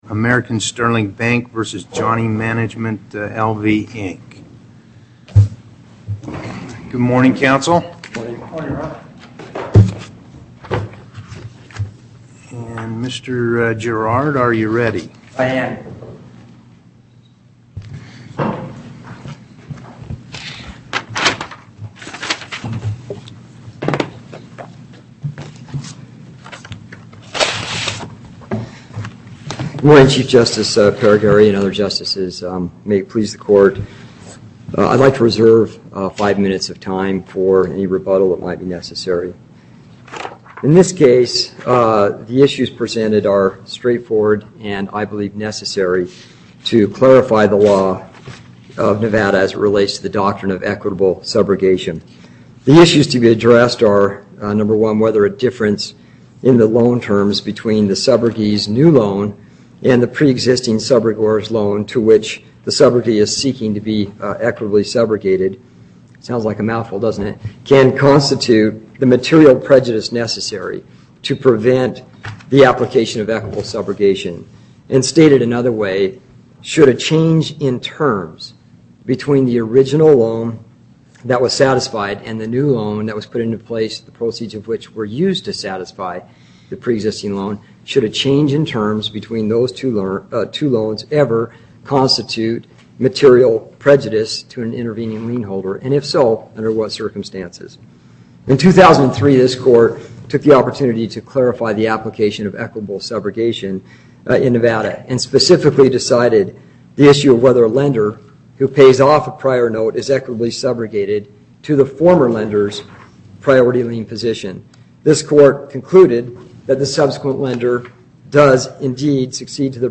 Location: Carson City Before the En Banc Court: Chief Justice Parraguirre, Presiding